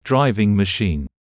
24.driving machine /ˈdraɪvɪŋ məˈʃiːn/ (n): máy lái xe